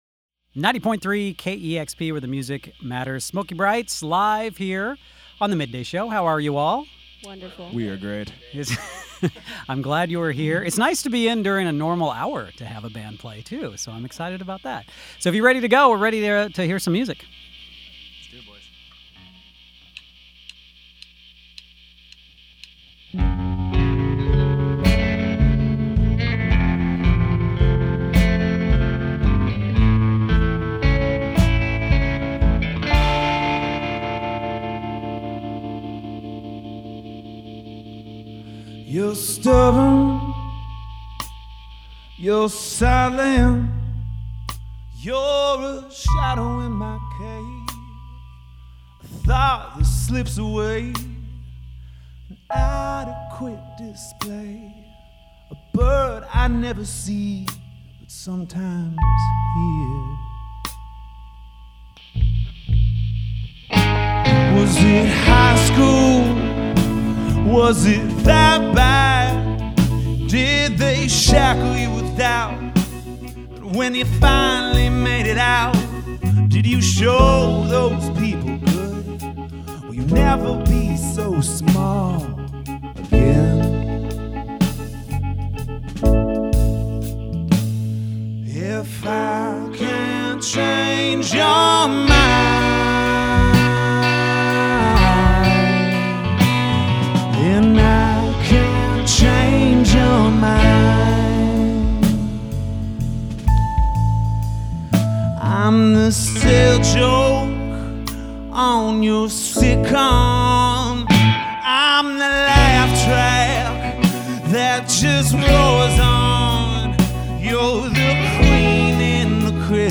Seattle 5-piece